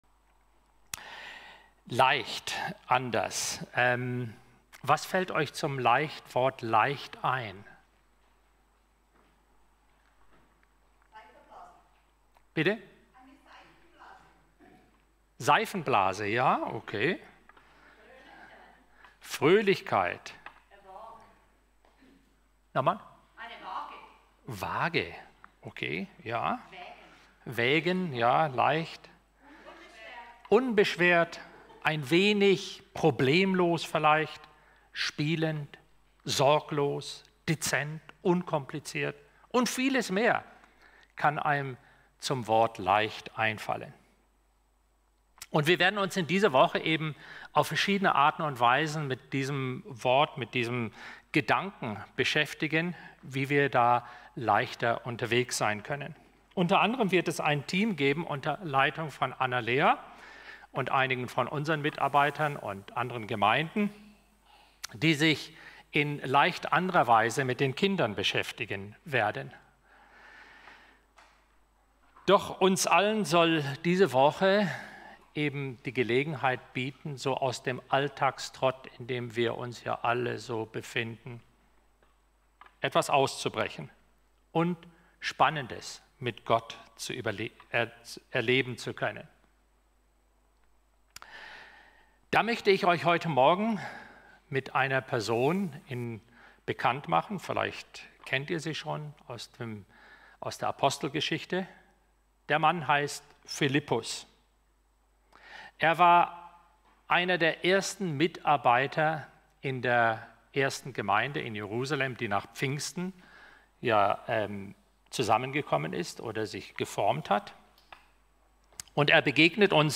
Gottesdienst：-Leicht-anders-unterwegs.mp3